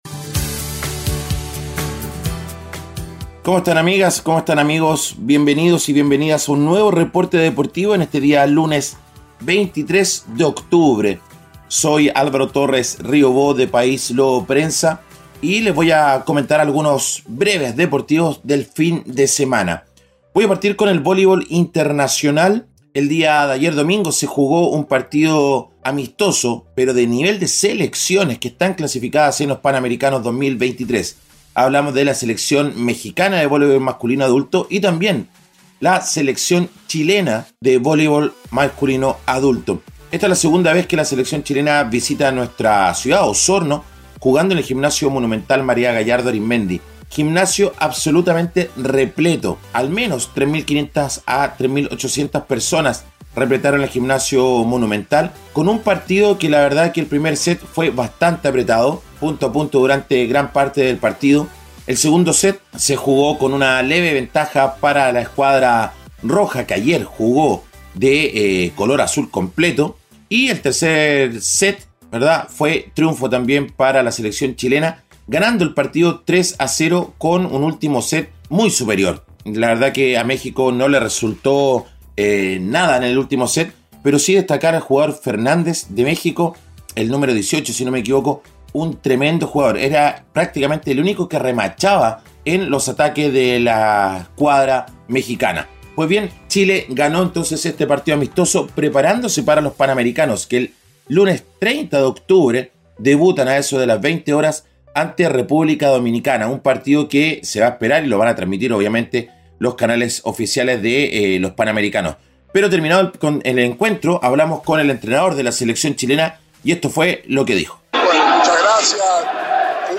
Reporte Deportivo 🎙 Podcast 23 de octubre de 2023